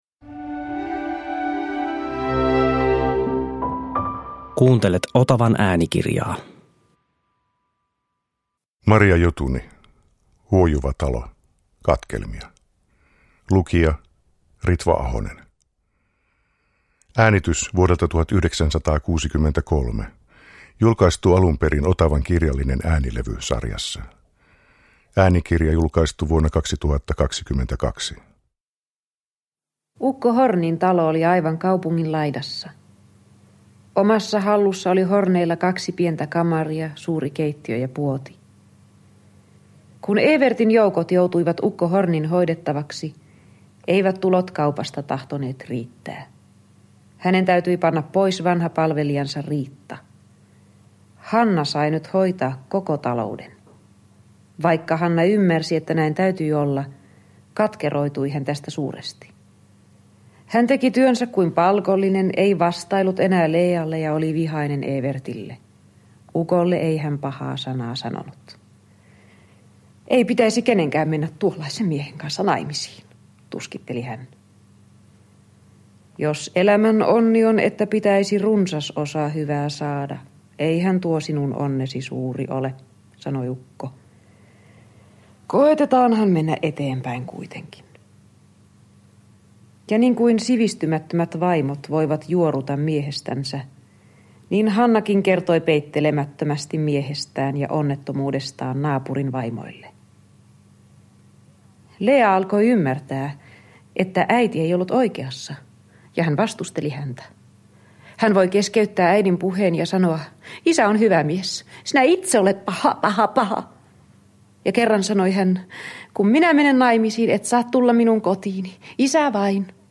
Huojuva talo - katkelmia – Ljudbok – Laddas ner